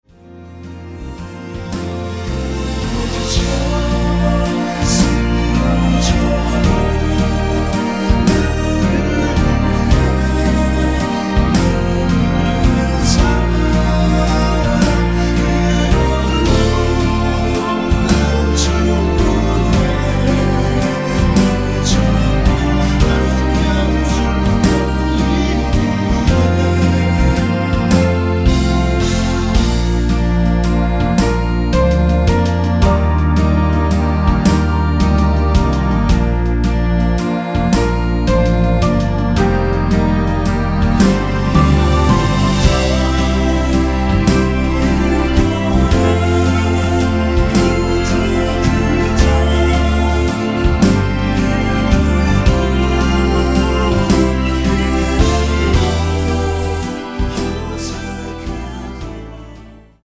(-2)내린 코러스 포함된 MR 입니다.(미리듣기 참조)
Bb
앞부분30초, 뒷부분30초씩 편집해서 올려 드리고 있습니다.
중간에 음이 끈어지고 다시 나오는 이유는